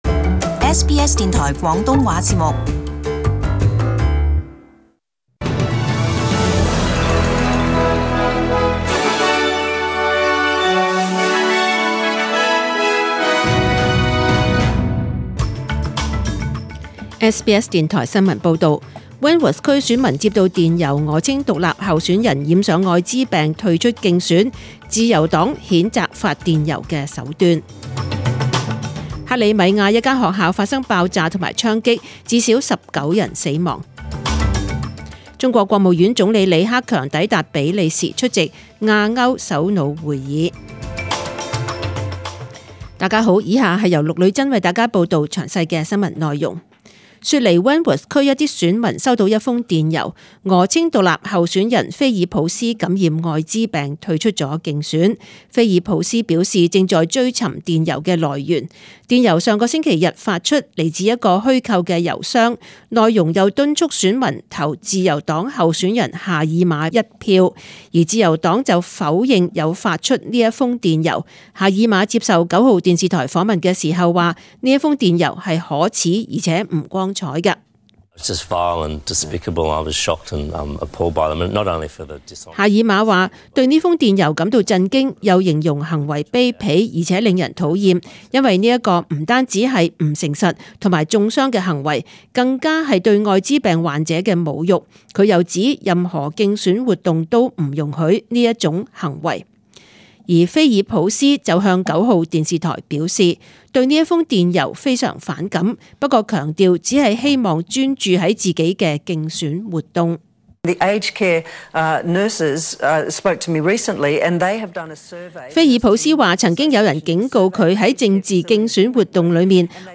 SBS中文新闻 （十月十八日）
请收听本台为大家准备的详尽早晨新闻。